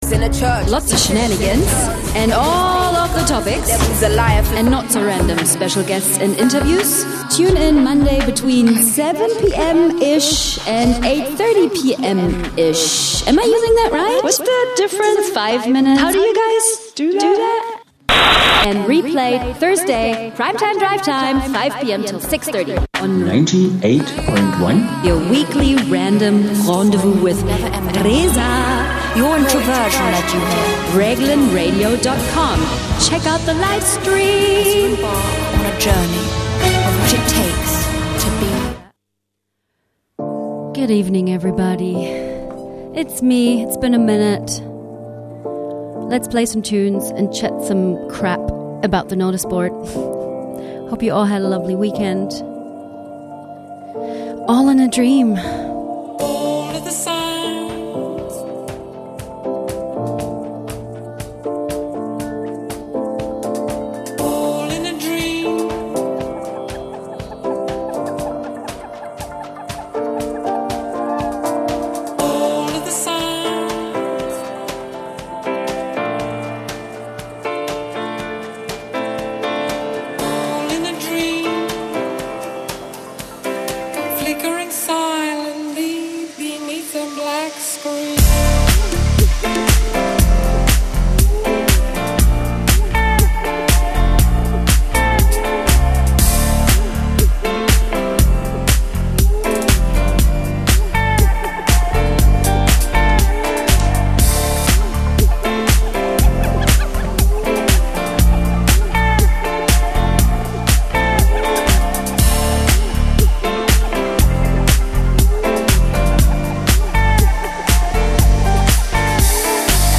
Oh and there was music too.